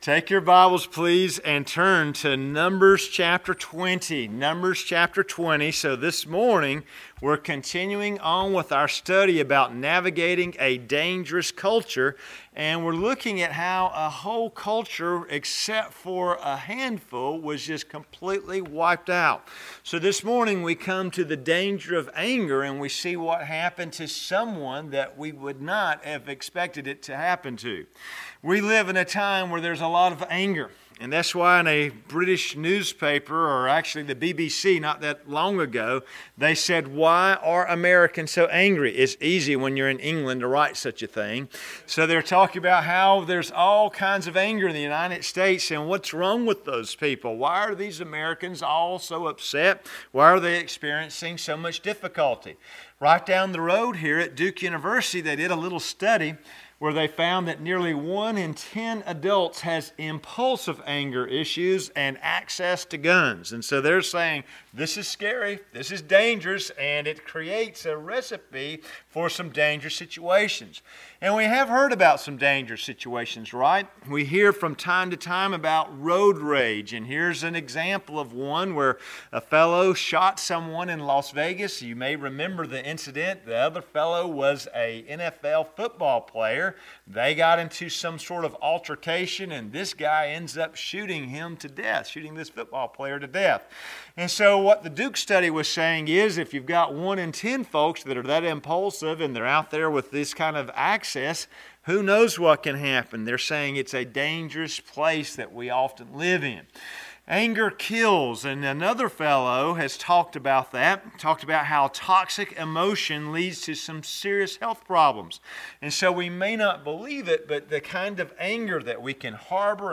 Sermons - Tippett's Chapel Free Will Baptist Church
Sermon library of Tippett’s Chapel Free Will Baptist Church in Clayton, NC enables listeners to easily browse our Sunday morning sermons and worship services.